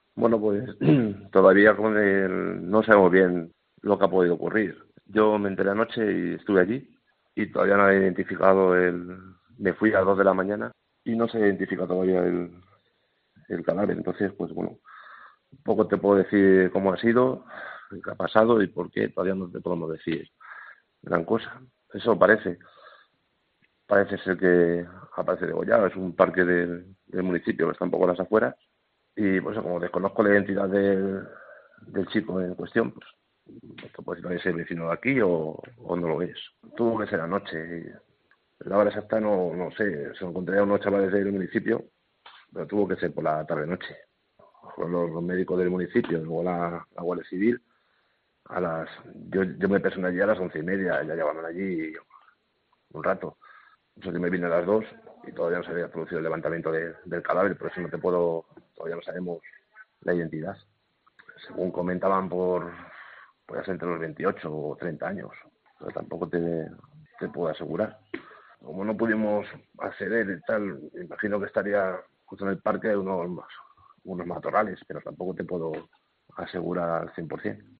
Entrevista al alcalde de La Pueblanueva